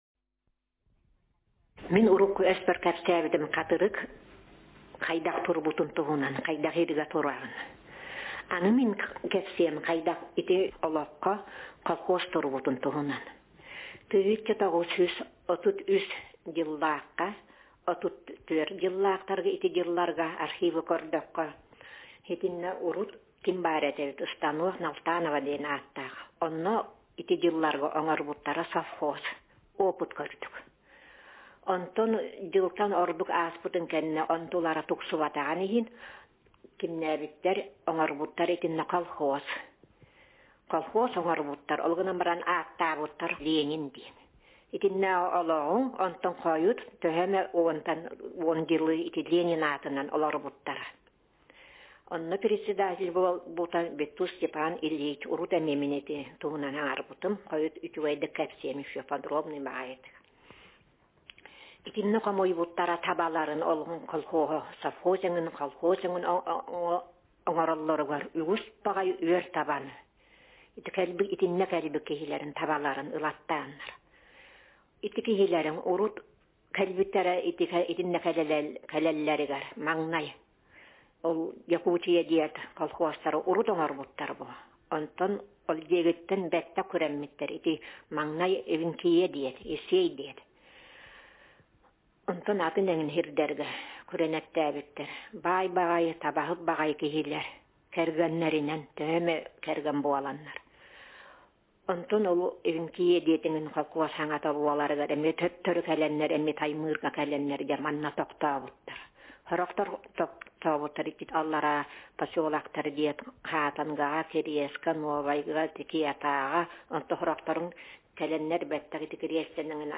Speaker sex f Text genre personal narrative